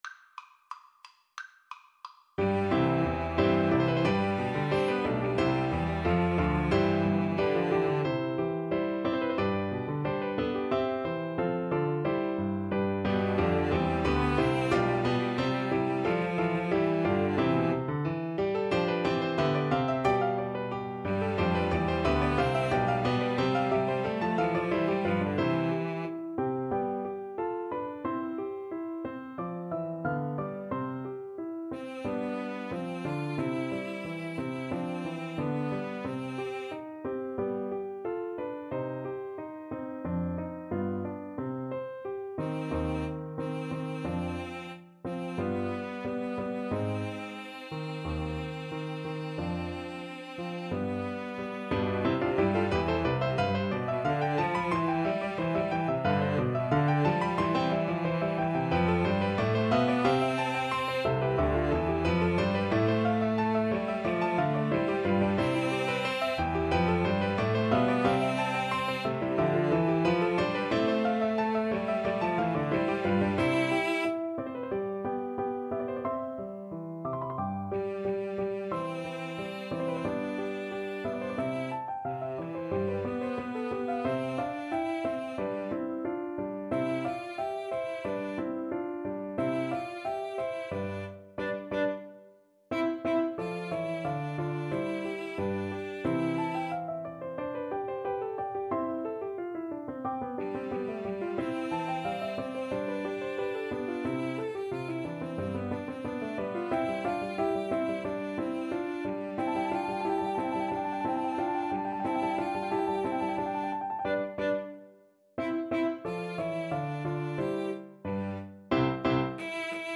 Allegro =180 (View more music marked Allegro)
4/4 (View more 4/4 Music)
Classical (View more Classical Piano Trio Music)